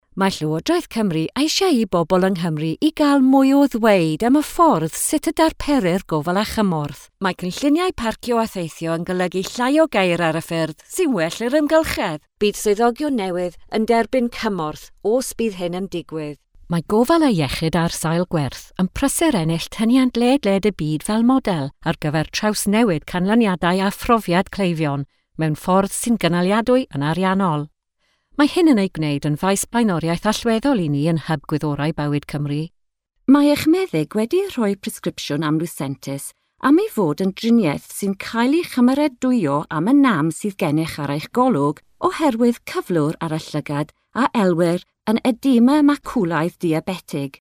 Welsh Language Corporate Showreel
Female
Friendly
Reassuring
Warm
Informative